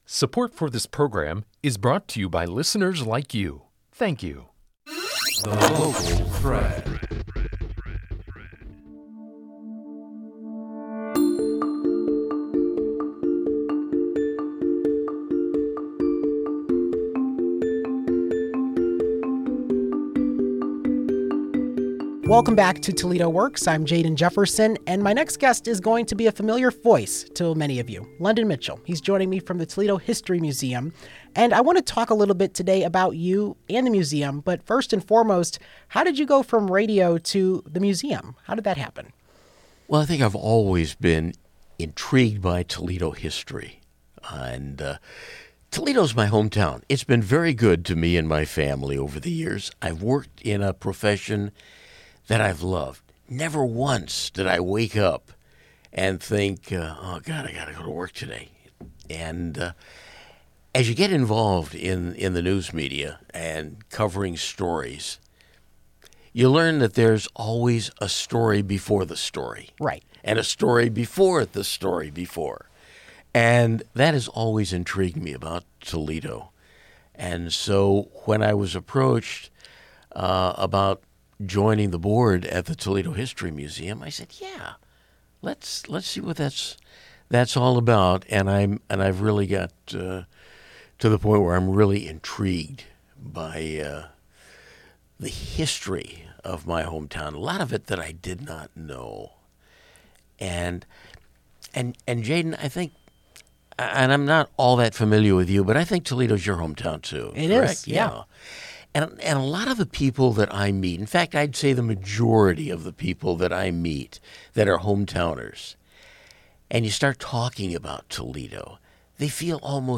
/*-->*/ In this week's episode of Toledo Works, we're featuring some of our favorite interviews.